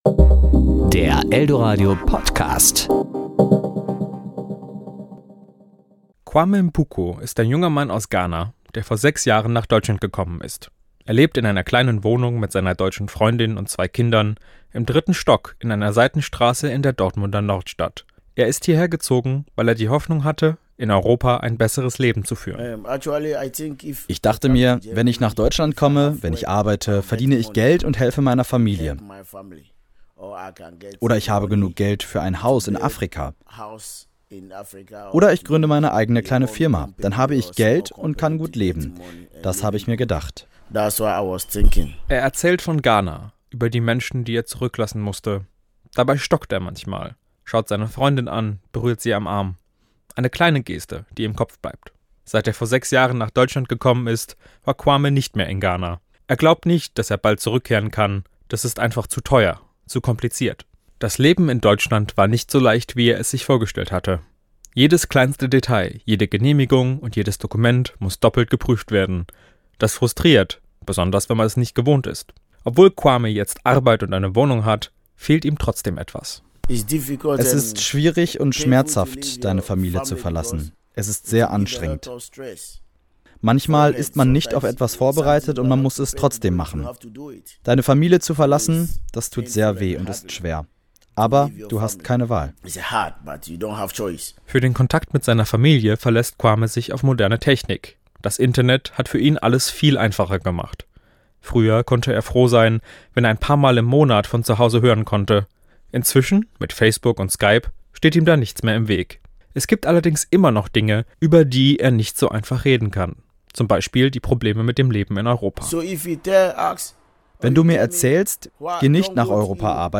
Wie fühlen sich Migranten in Deutschland, und wie sprechen sie mit Familie und Freunden in der Heimat über ihre Erfahrungen? Ein junger Ghanaer aus der Nordstadt berichtet.